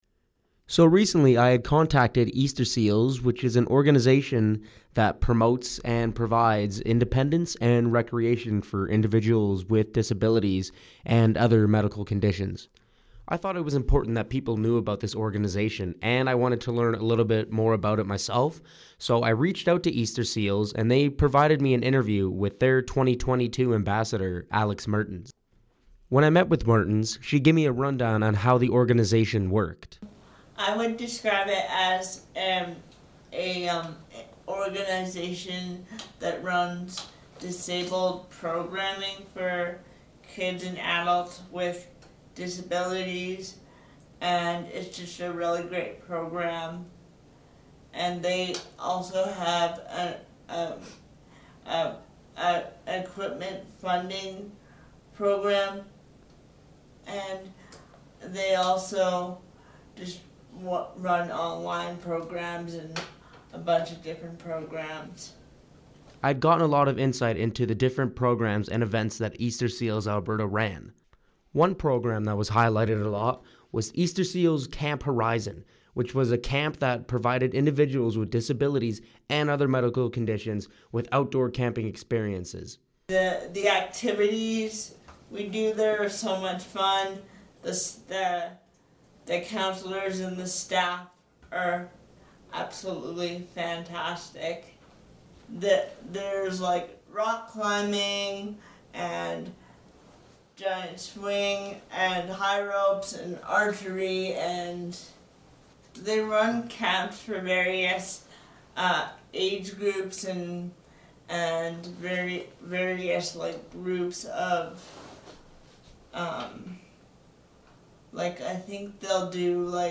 Easter Seals Audio Interview I had learnt a lot during this interview and understood the values that Easter Seals hold and should be known for helping with inclusion of peoples with disabilities and medical conditions.